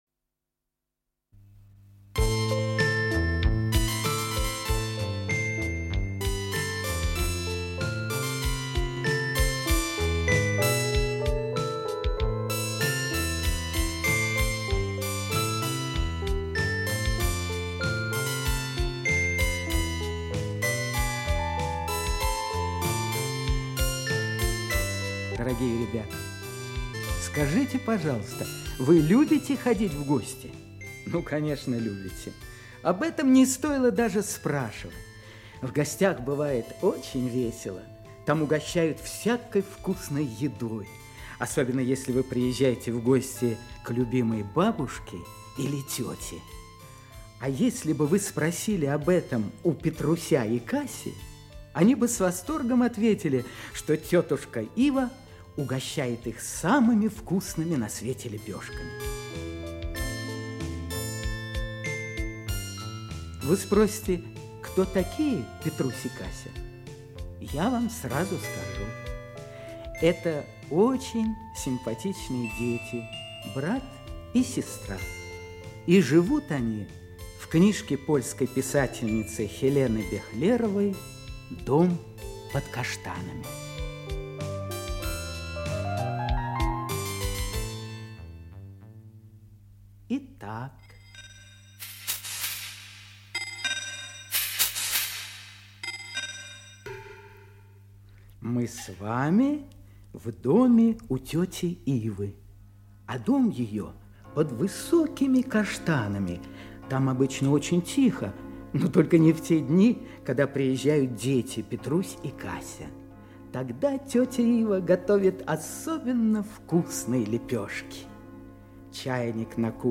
Аудиокнига Дом под каштанами | Библиотека аудиокниг
Aудиокнига Дом под каштанами Автор Хелена Бехлерова Читает аудиокнигу Актерский коллектив.